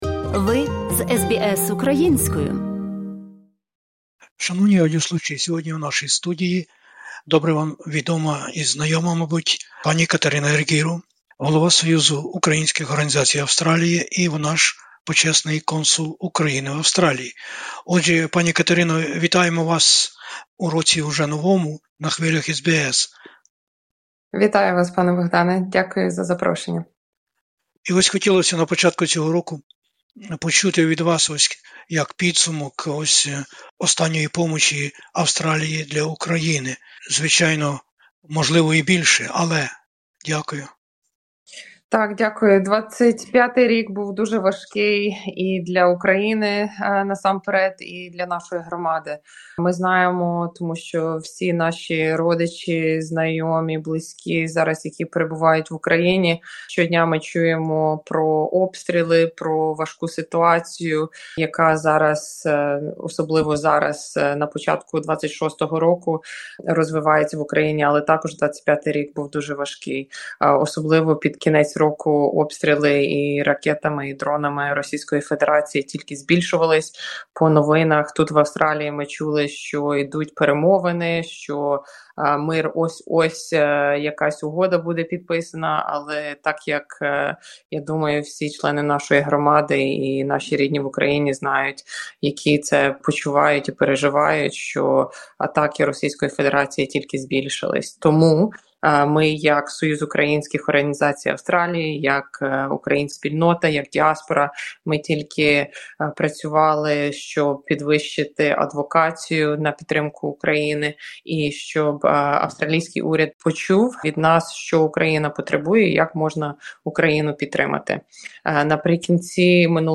У розмові з SBS Ukrainian високодостойна п-і Катерина Арґіру, Голова Управи Союзу Українських Організацій Австралії, Почесний консул України у Сіднеї та членкиня Ради директорів Світового Конґресу Українців, розповідає про подорож в Україну та дні насущні української діаспори та її прагнення допомогти Україні відстояти незалежність....